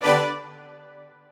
admin-leaf-alice-in-misanthrope/strings34_1_023.ogg at main